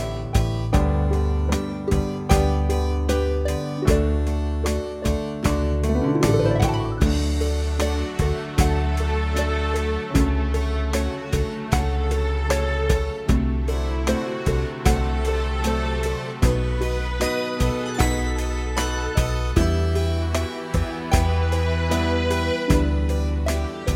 Jazz / Swing